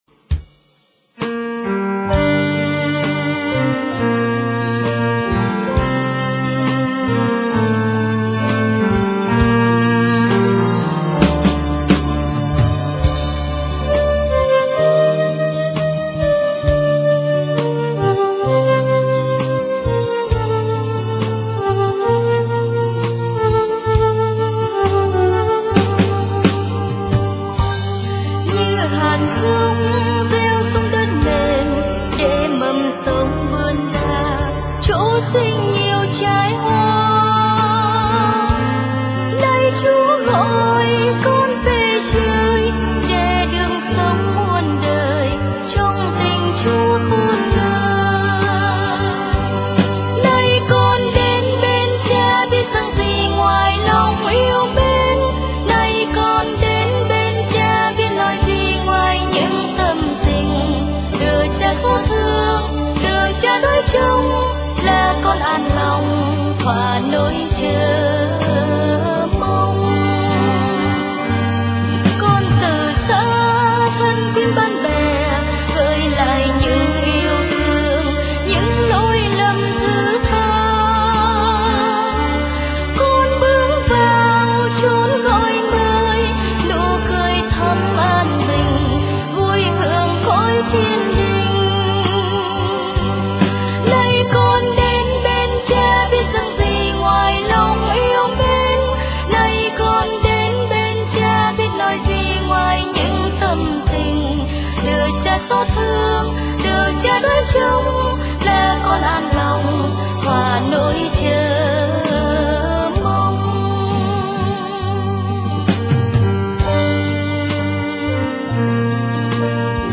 * Thể loại: Cầu hồn